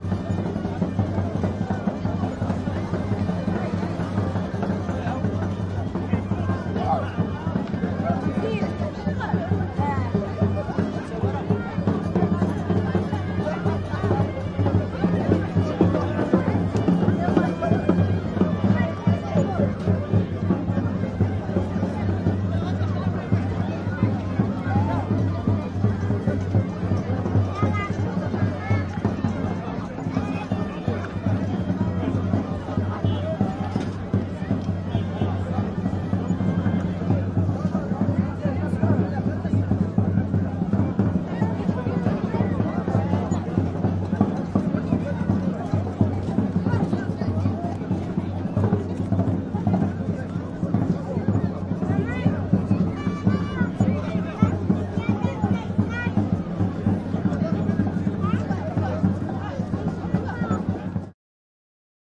描述：清晨的鸟鸣和其他背景噪音，在摩洛哥马拉喀什的一个Riad屋顶上录制。
标签： 非洲 鸟鸣 马拉喀什 早晨 摩洛哥
声道立体声